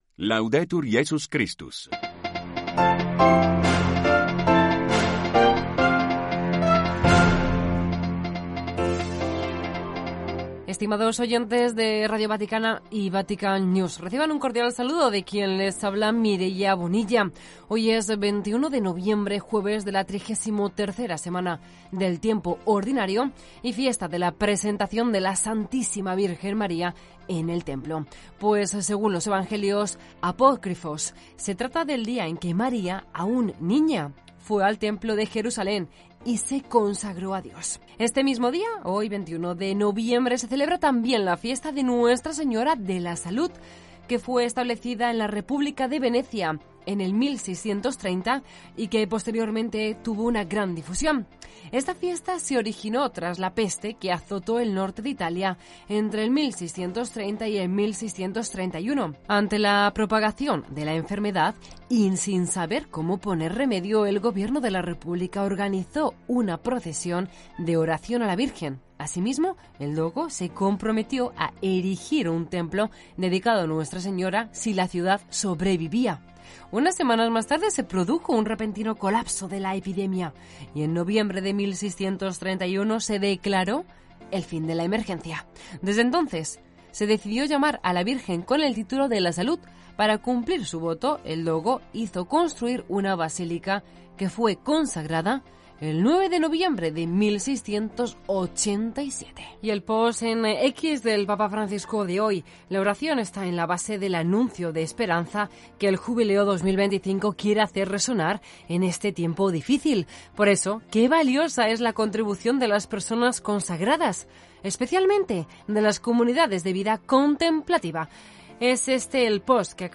Informativos diarios en español